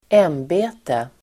Uttal: [²'em:be:te]